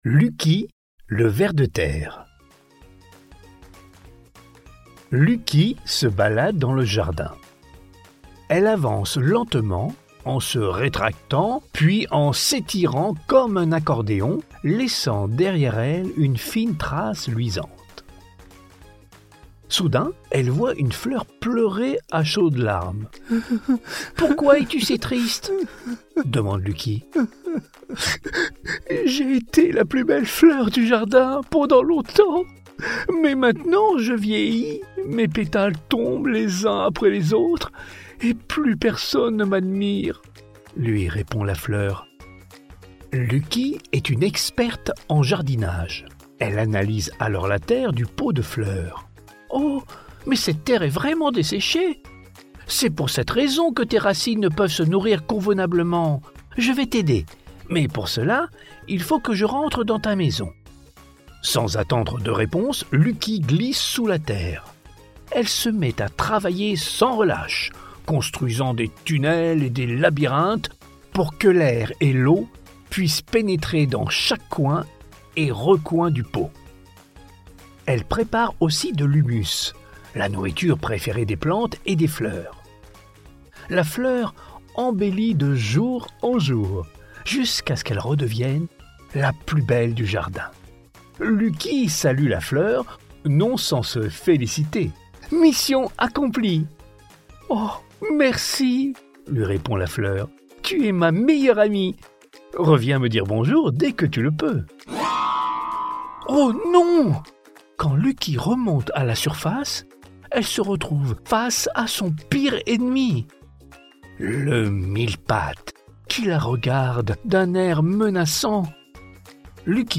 Ces livres audio pour enfants sont parfaits pour les trajets en voiture, les moments de détente ou les rituels du coucher. Chaque récit offre une narration chaleureuse et engageante, soutenue par une ambiance sonore douce qui capte l'attention des petits sans les surstimuler.